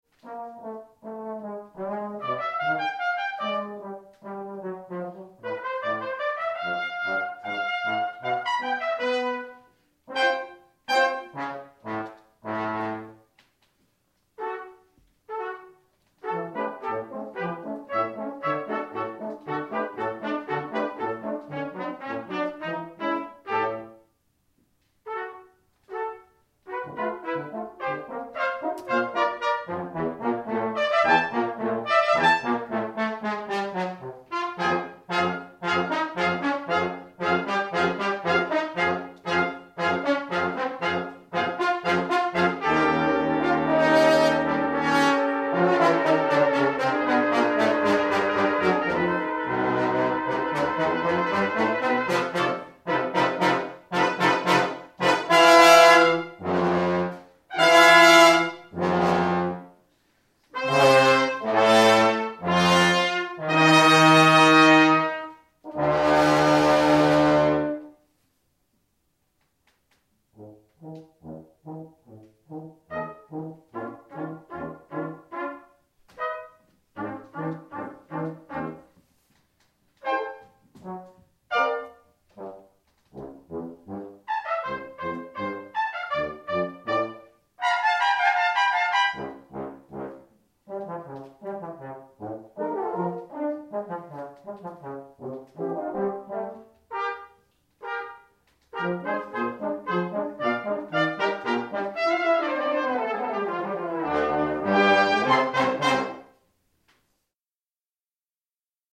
Cornet
Trompete
Tuba
Horn
Posaune